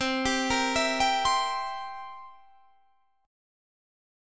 C7 Chord
Listen to C7 strummed